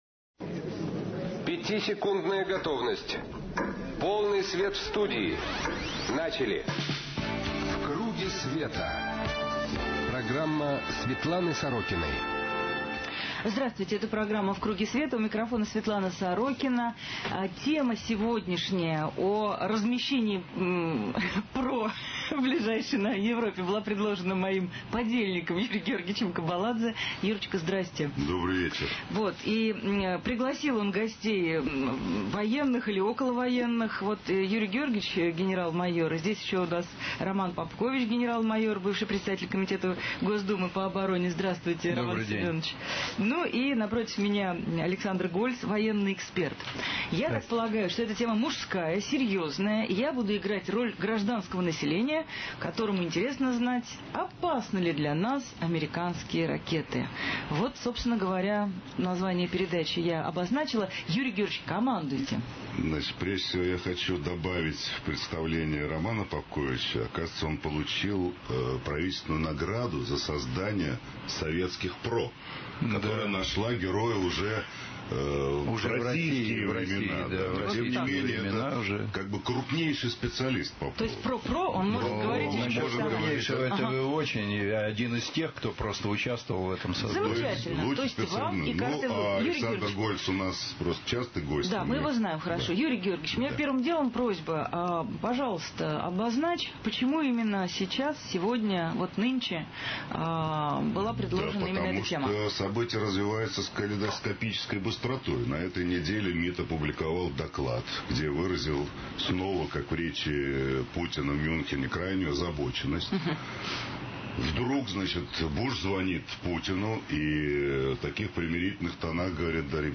В круге СВЕТА. Светлана Сорокина на «Эхе Москвы»
Светлана Сорокина: передачи, интервью, публикации